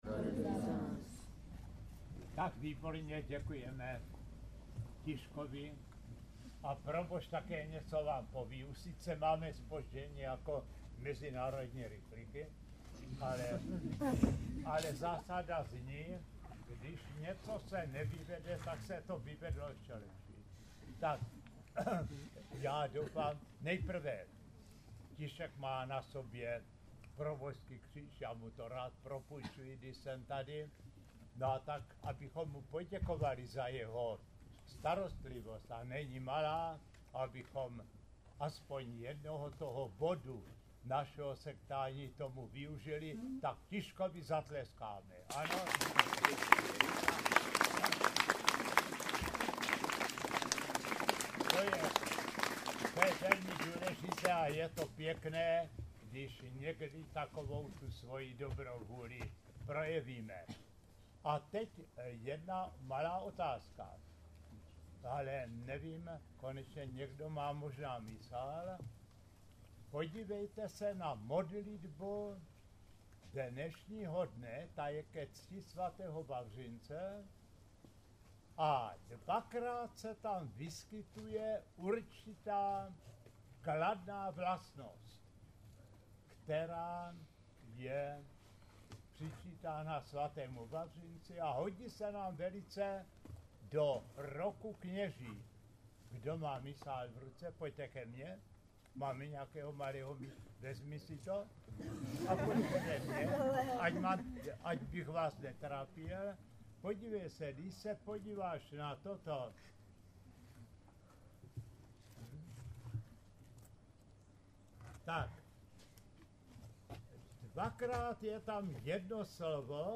Nabízíme vám text a audiozáznam jeho pondělní promluvy při mši.